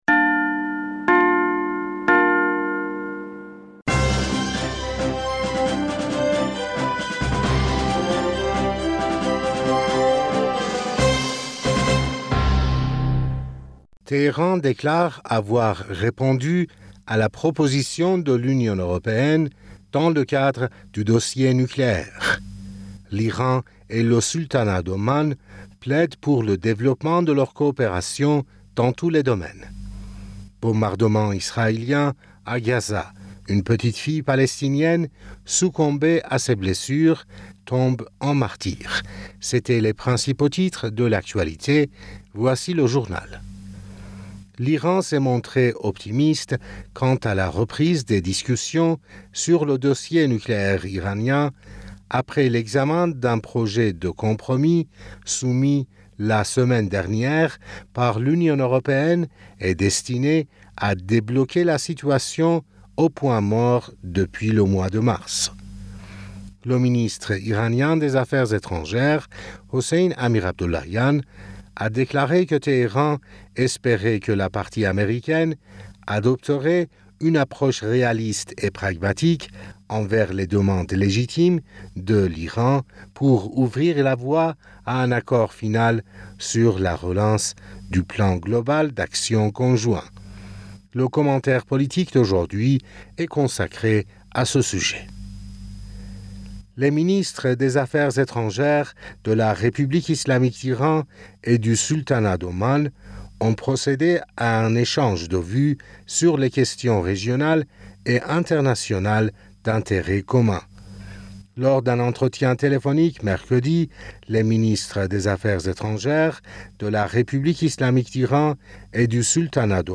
Bulletin d'information Du 11 Aoùt